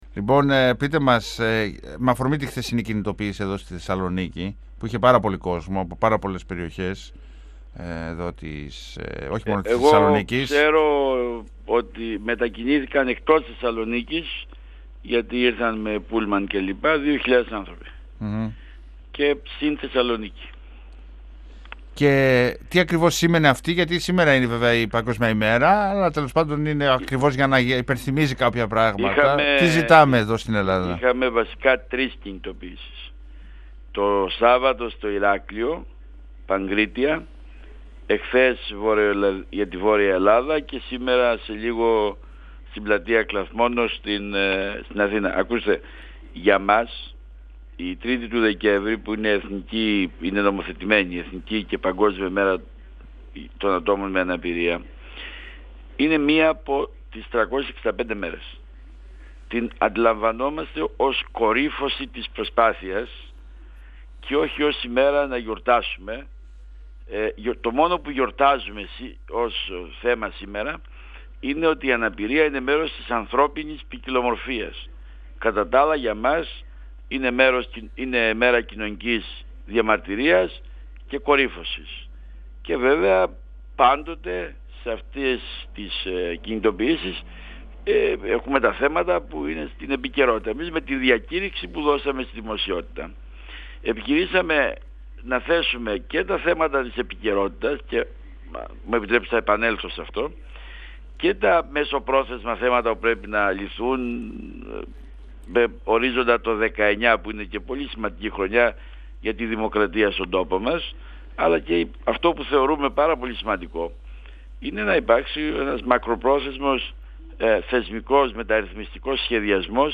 με ρύθμιση που θα προωθηθεί τον ερχόμενο Φεβρουάριο. 102FM Συνεντεύξεις ΕΡΤ3